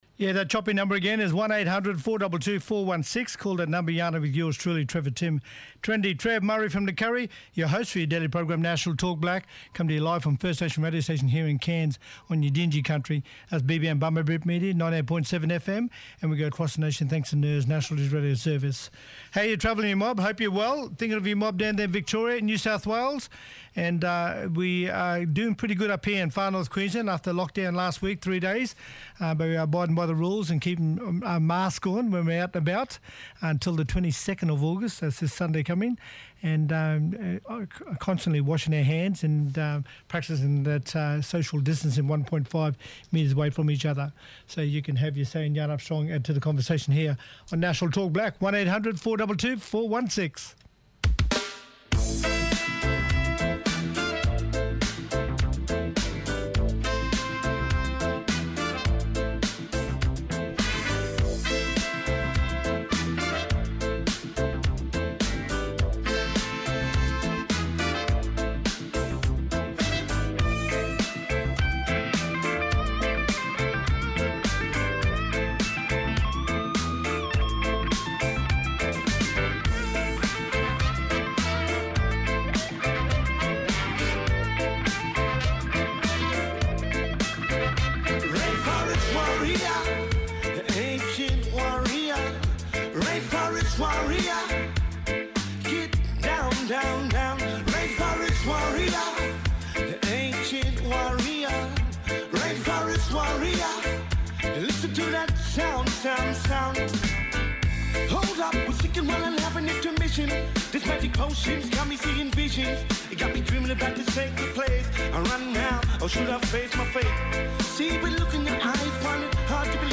Ross Andrews, Mayor of Yarrabah Aboriginal Shire Council talking about the recent Cairns and Yarrabah lockdown that took place last week.